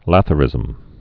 (lăthə-rĭzəm)